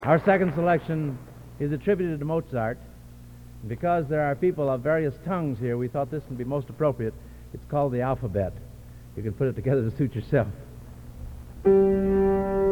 Collection: PVGC with Home Demonstration Choir
Genre: | Type: Director intros, emceeing